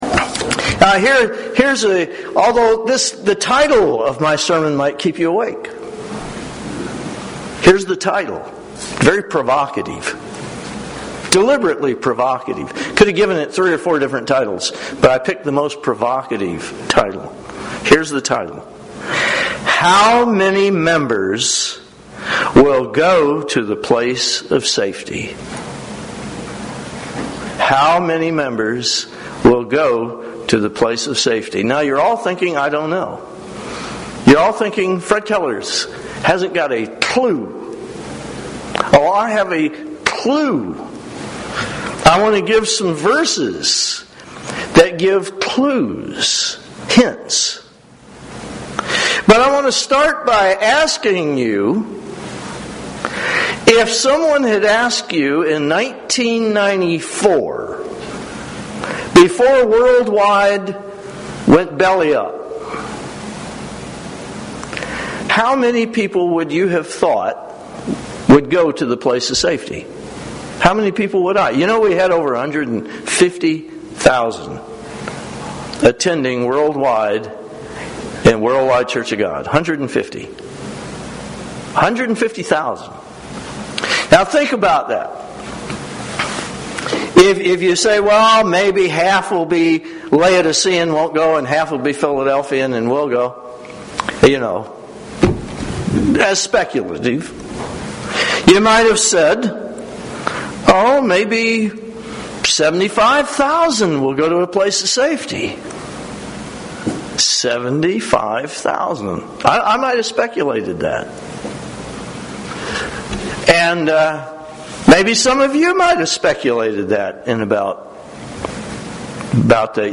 This sermon helps to explain today's events from a biblical perspective.
Given in Grand Rapids, MI